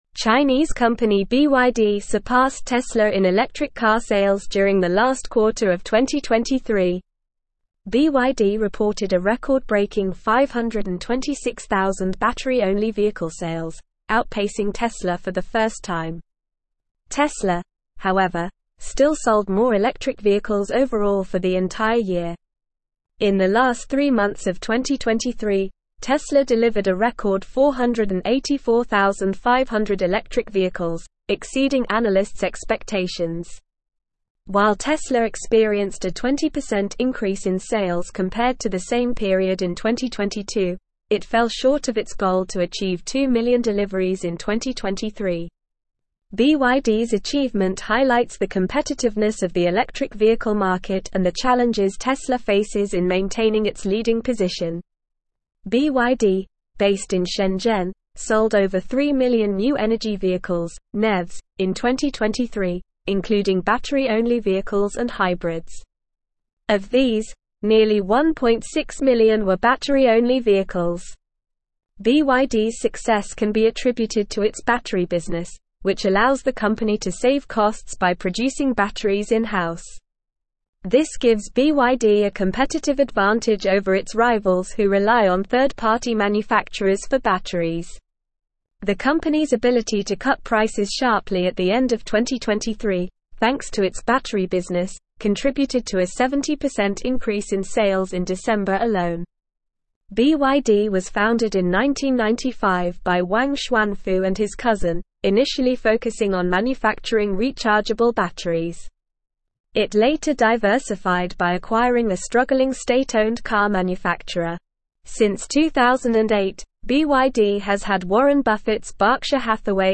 Normal
English-Newsroom-Advanced-NORMAL-Reading-BYD-Overtakes-Tesla-in-Electric-Vehicle-Sales.mp3